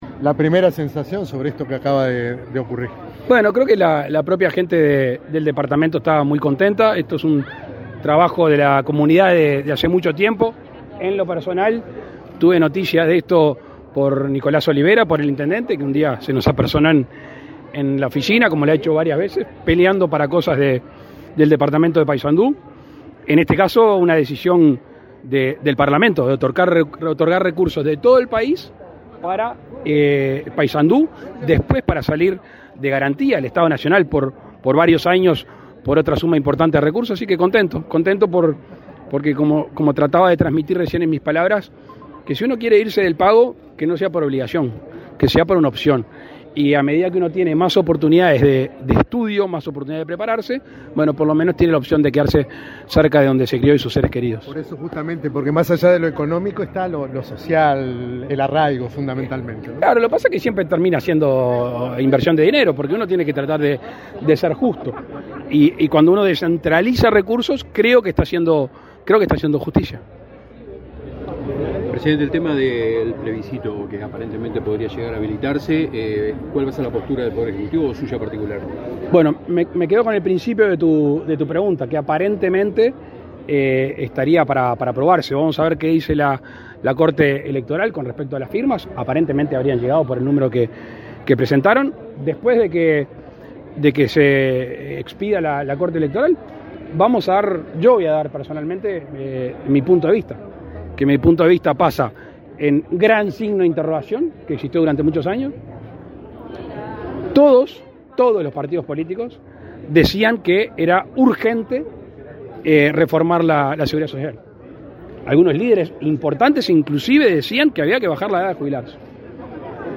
Declaraciones a la prensa del presidente de la República, Luis Lacalle Pou
Tras participar en la firma de convenio de financiamiento de la nueva sede de la Universidad de la República (Udealar) en Paysandú, entre la Intendencia de Paysandú, la Udelar y el Banco de Desarrollo de América Latina y el Caribe (CAF), este 7 de mayo, el presidente de la República, Luis Lacalle Pou, realizó declaraciones a la prensa.
lacalle prensa.mp3